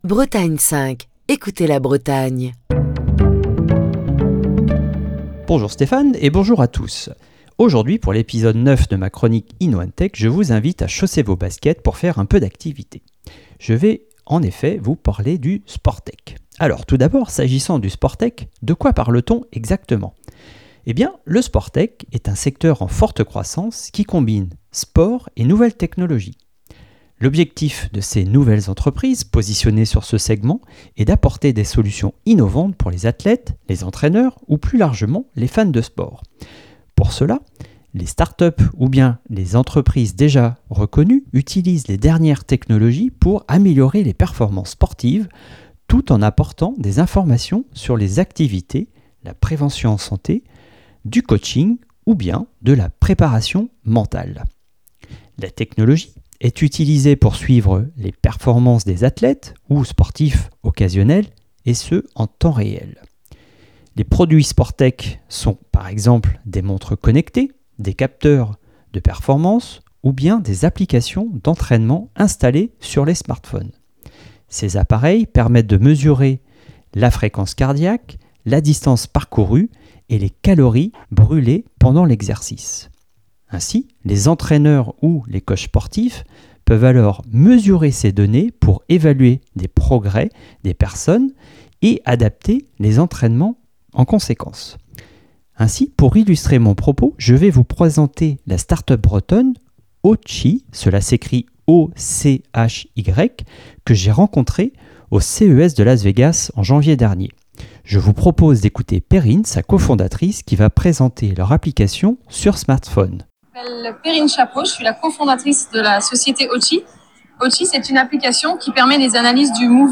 Chronique du 29 mars 2023. Aujourd'hui, le sport n'échappe pas aux nouvelles technologies, que ce soit au niveau professionnel pour la préparation des athlètes, ou au niveau des sportifs amateurs pour améliorer leurs performances.